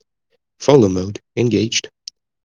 follow-mode-engaged.wav